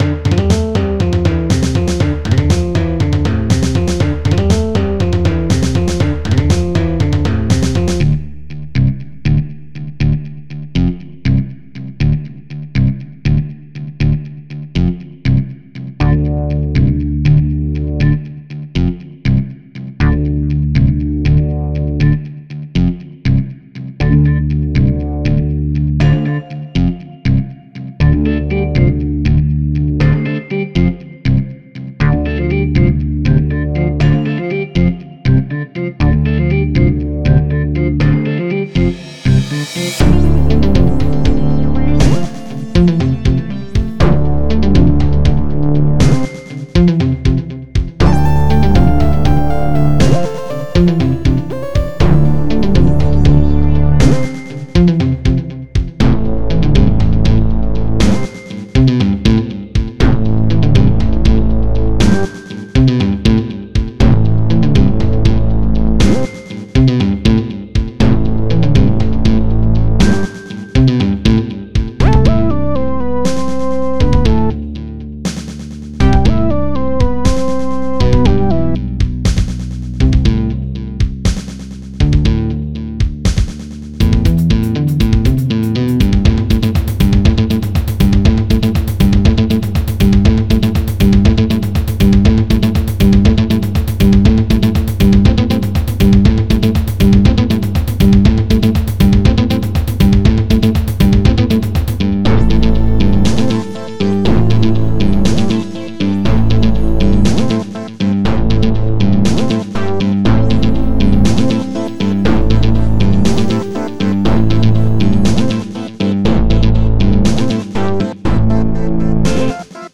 These are some songs from when I was less experienced at making music on the computer.